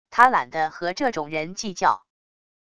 他懒得和这种人计较wav音频生成系统WAV Audio Player